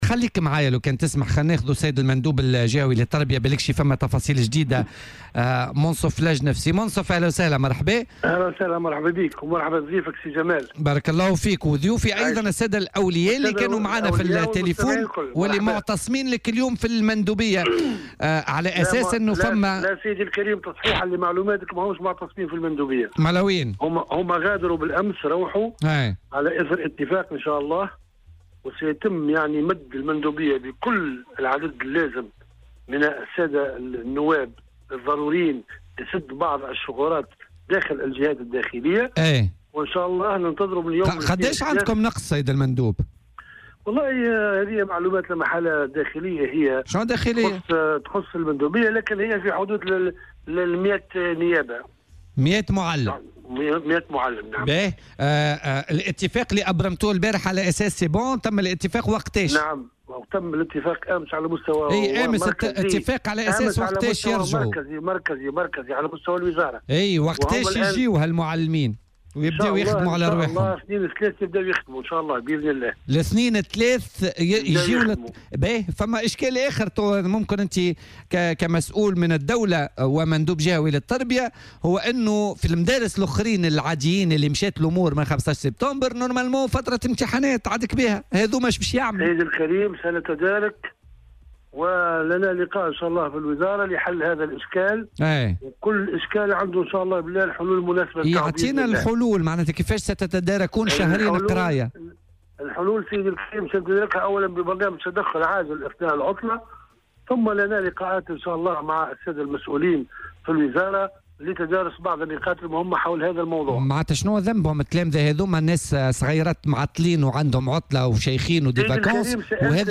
قال المندوب الجهوي للتربية بالمهدية، منصف لجنف، في مداخلة هاتفية مع "بوليتيكا" على "الجوهرة أف أم" اليوم الخميس إن الدروس ستستأنف بداية الأسبوع المقبل بعدد من مدارس الجهة بعد أن بقيت أبوابها مغلقة منذ انطلاق السنة الدّراسية الحالية.